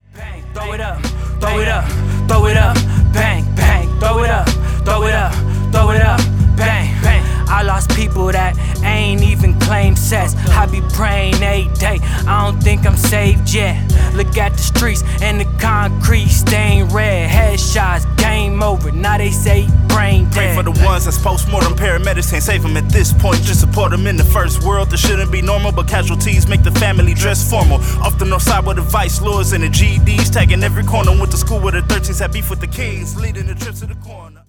Rap
Rap.mp3